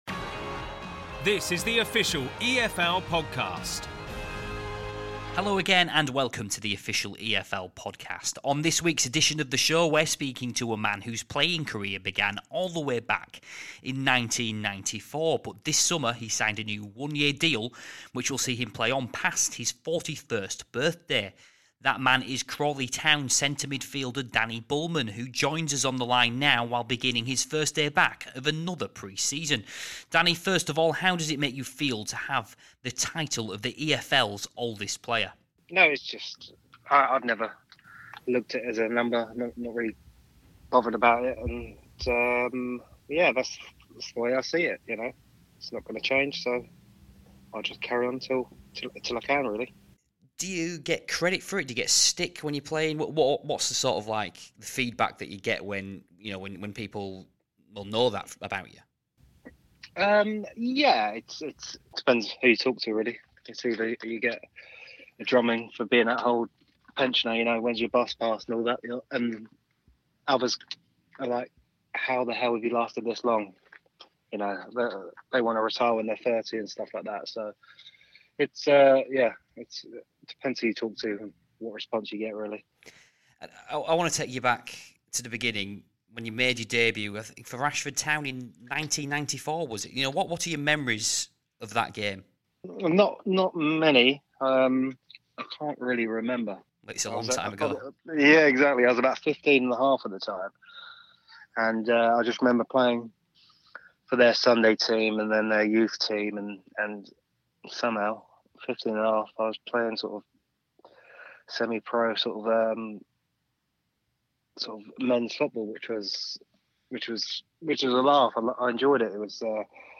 There is also an interview with the EFL's Young Player of the Month